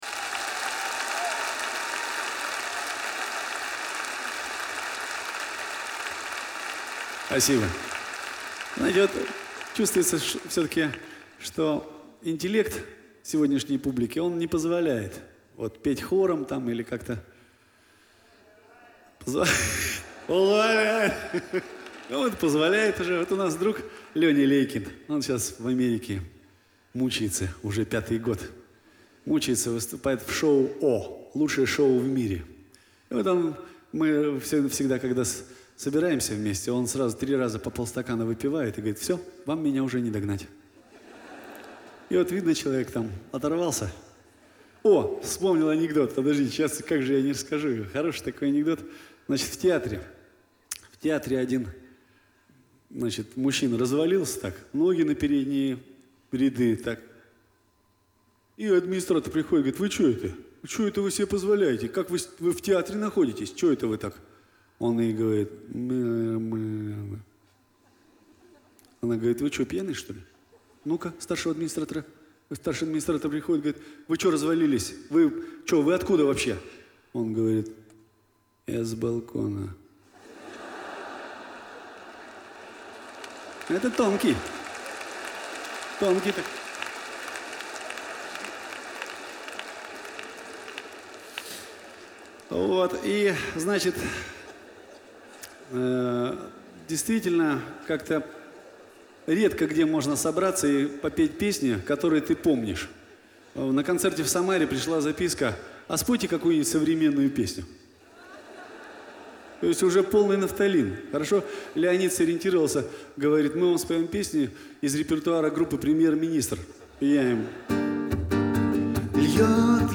Трёп